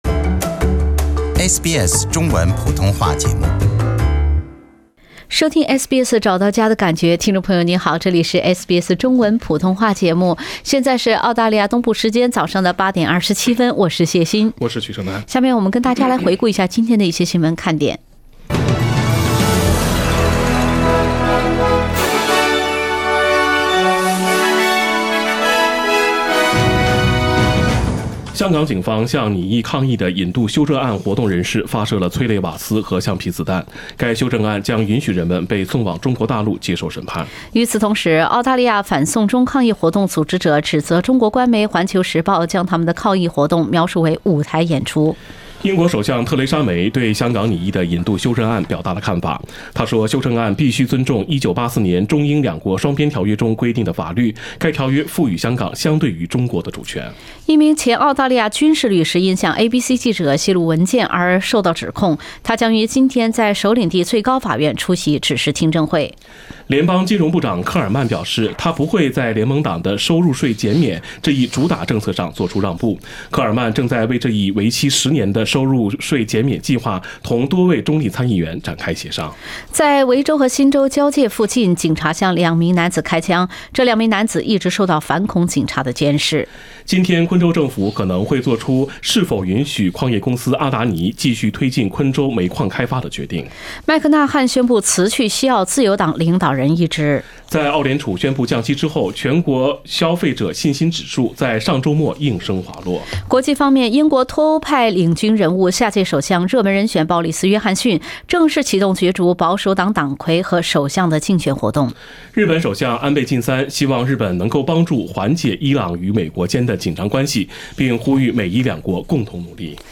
SBS早新闻 （6月13日）